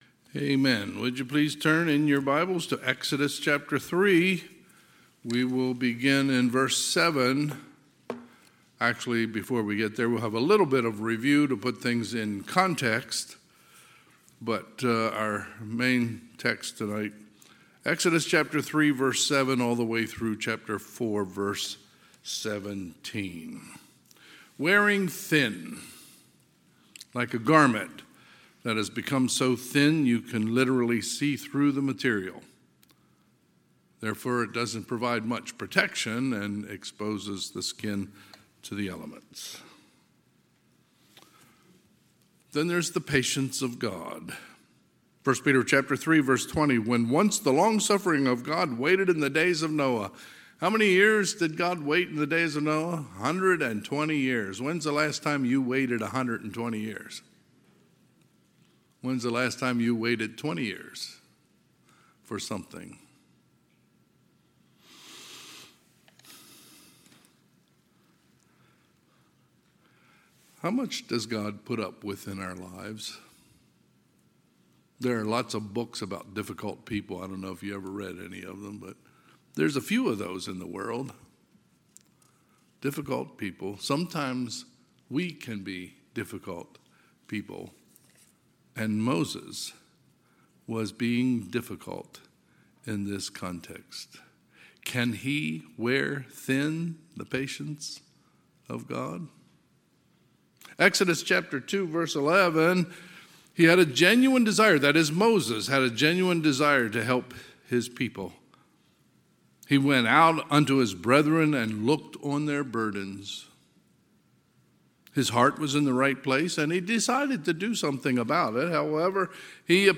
Sunday, June 4, 2023 – Sunday PM
Sermons